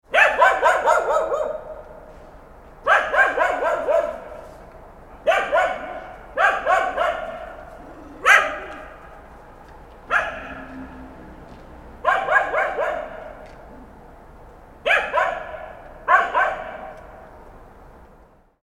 Realistic Dog Bark Sound Effect
Description: Realistic dog bark sound effect. The noise of an aggressive dog barking.
Realistic-dog-bark-sound-effect.mp3